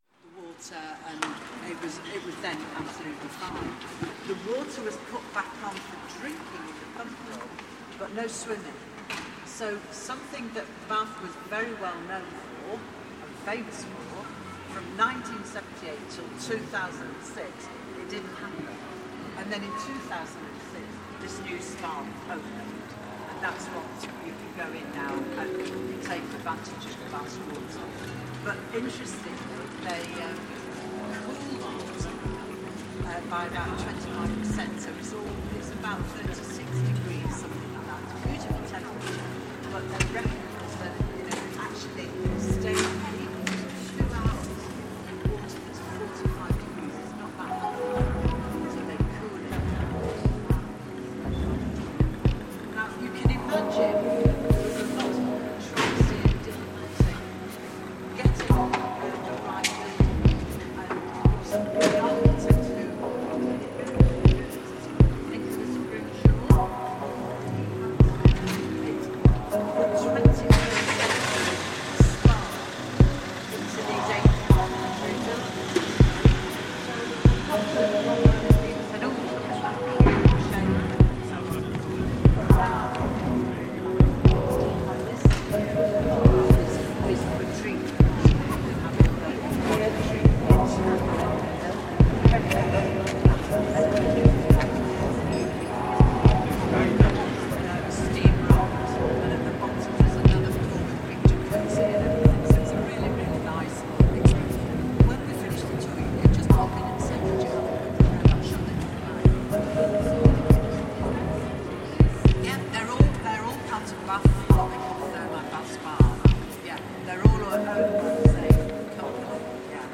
reimagined sound from Bath Thermae Spa